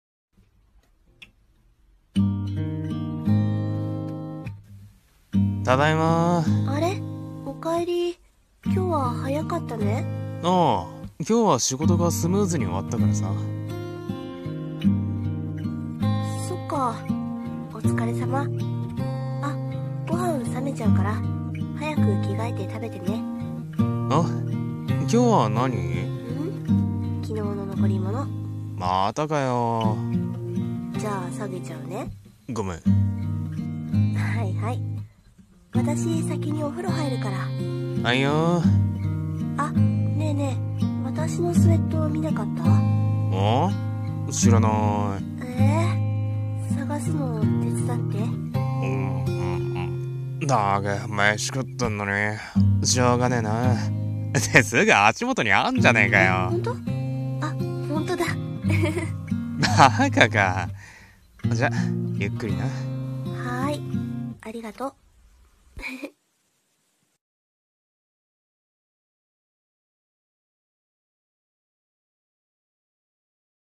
〈二人声劇〉いつもの僕と君の コラボ用